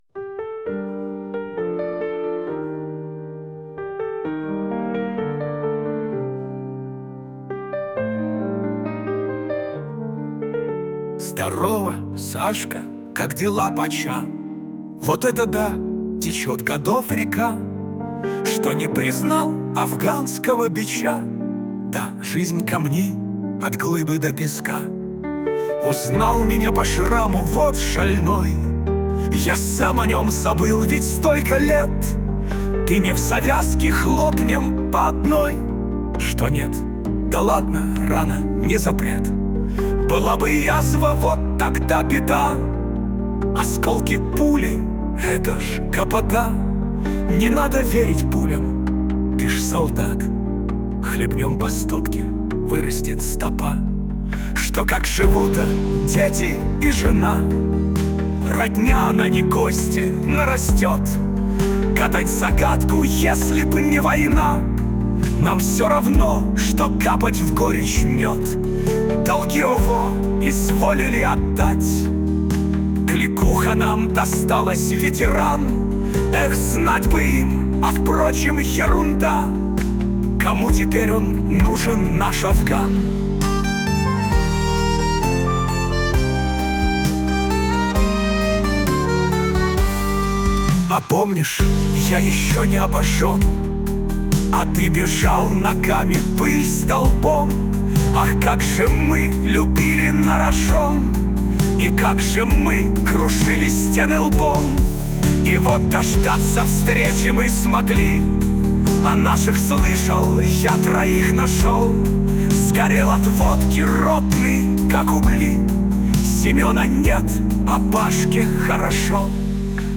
sashka4.mp3 (5204k) Попытка песни ИИ